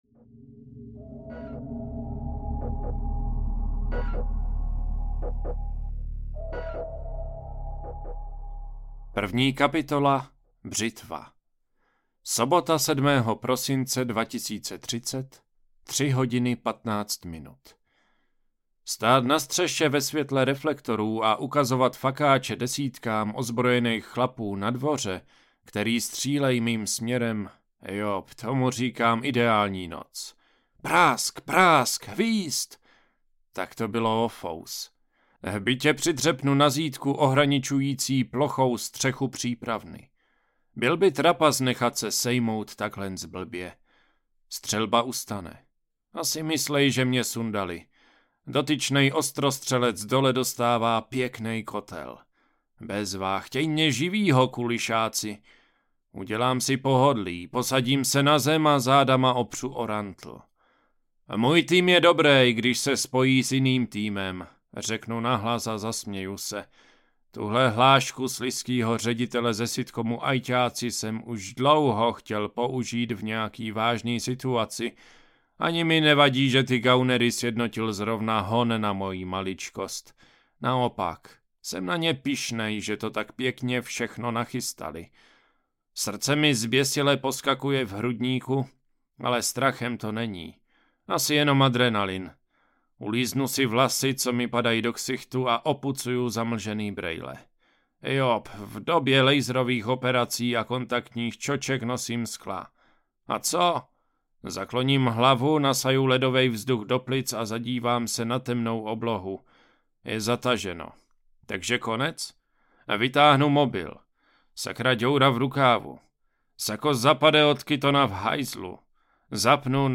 Bedny audiokniha
Ukázka z knihy
bedny-audiokniha